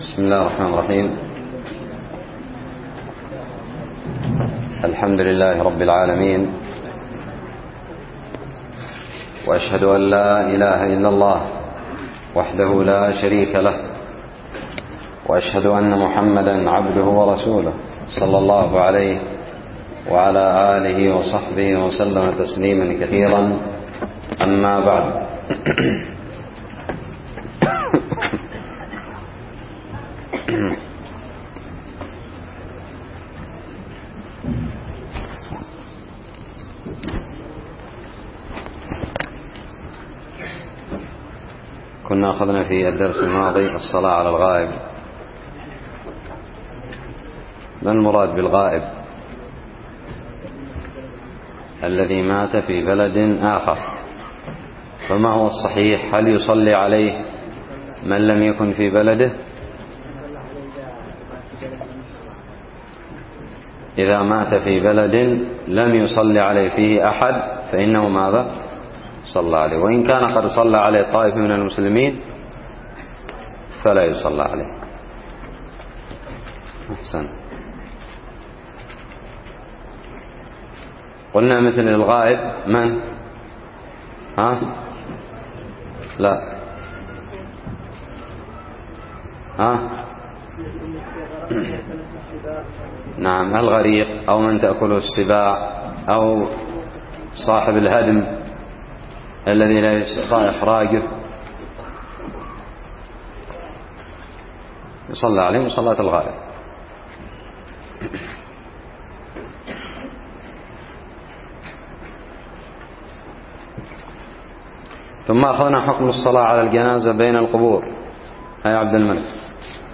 الدرس الثلاثون من كتاب الجنائز من الدراري
ألقيت بدار الحديث السلفية للعلوم الشرعية بالضالع